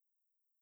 spinnerspin.wav